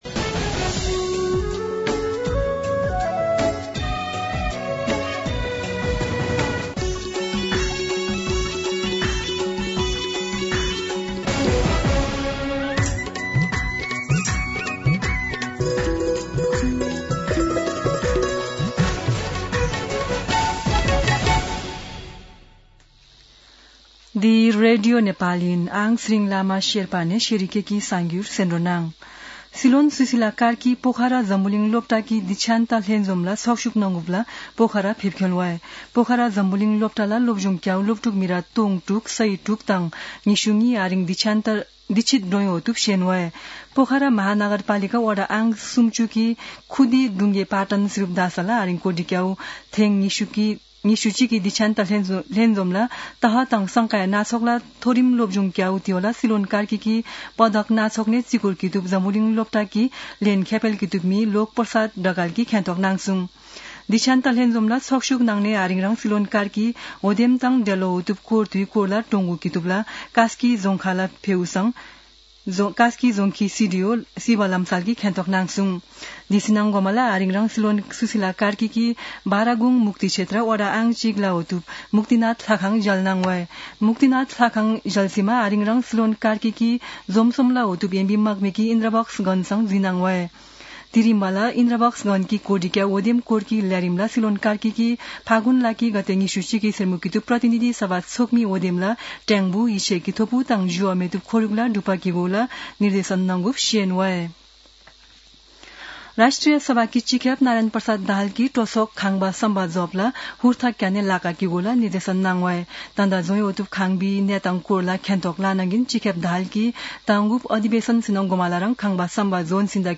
शेर्पा भाषाको समाचार : ८ फागुन , २०८२
Sherpa-News-11-8.mp3